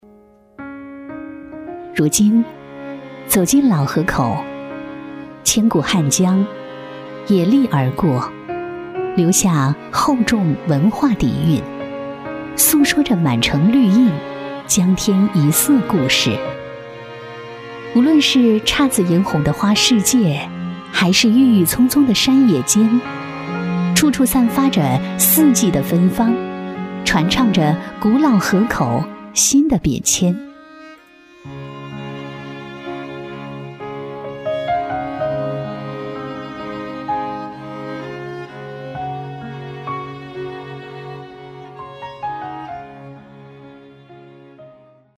职业配音员全职配音员成熟
• 女S114 国语 女声 宣传片 汉江城市宣传片 大气 沉稳 亲切甜美